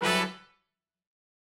GS_HornStab-Emin+9sus4.wav